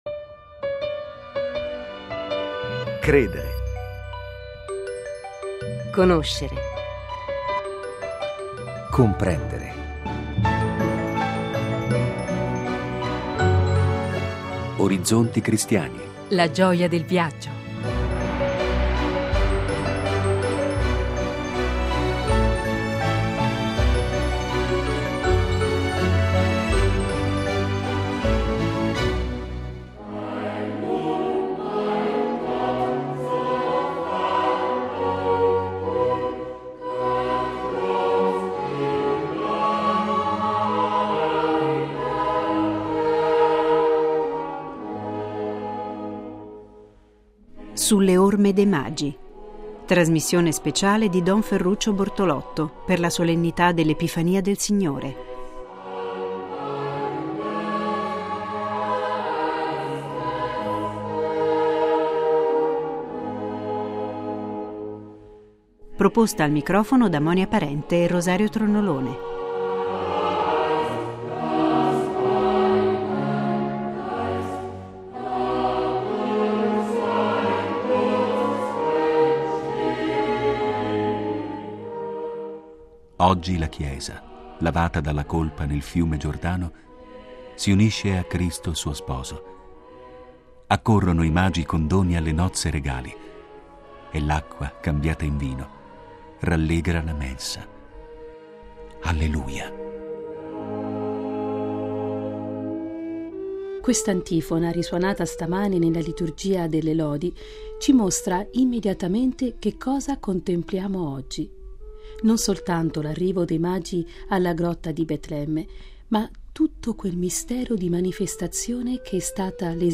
Una trasmissione speciale